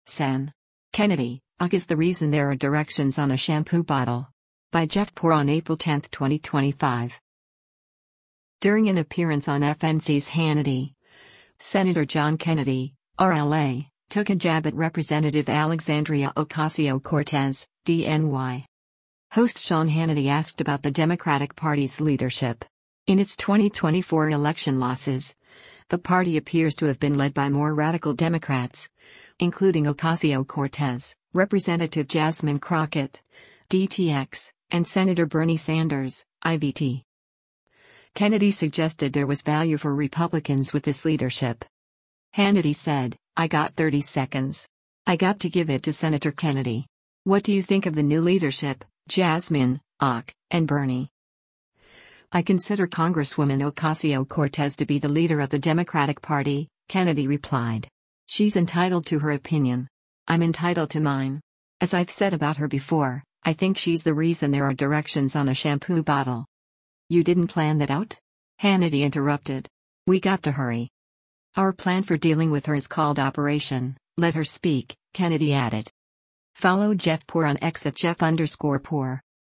During an appearance on FNC’s “Hannity,” Sen. John Kennedy (R-LA) took a jab at Rep. Alexandria Ocasio-Cortez (D-NY).
Host Sean Hannity asked about the Democratic Party’s leadership.